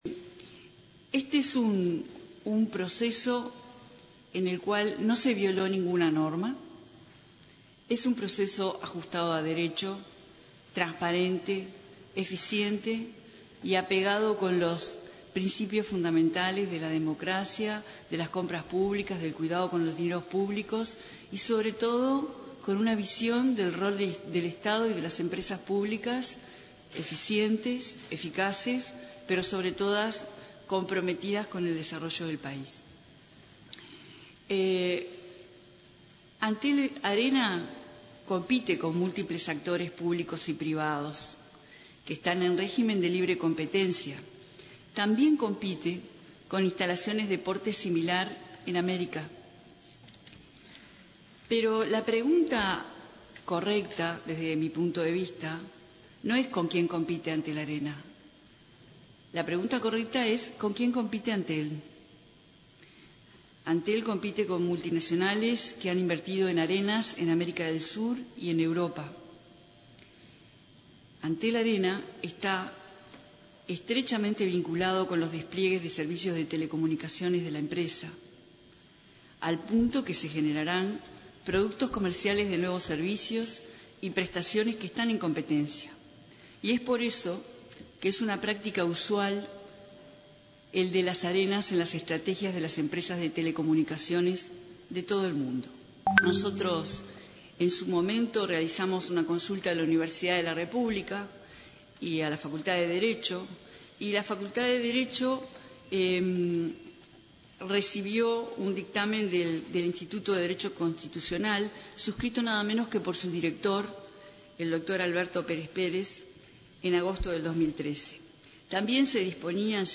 “Es un proceso en el que no se violó ninguna norma constitucional, según el Tribunal de lo Contencioso Administrativo y la Facultad de Derecho”, advirtió la ministra de Industria, Carolina Cosse este jueves, durante su comparecencia en la Cámara de Senadores a efectos de informar sobre el Antel Arena. El proyecto pasó de 36.000 a 48.000 metros cuadrados de construcción, más 9 hectáreas de parque urbano.